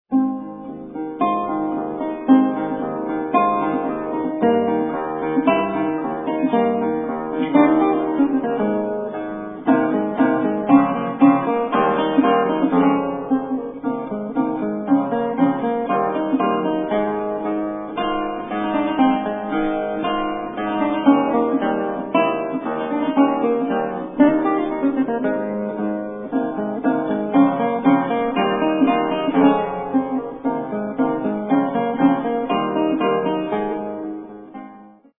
guitarist
tuned to low pitch A=415